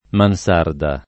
[ man S# rda ]